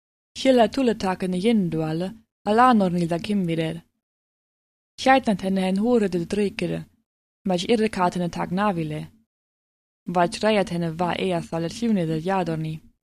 Bevor wir uns jetzt mit der Grammatik herumschlagen, folgt noch ein kleiner Text zum Einlesen in die Sprache: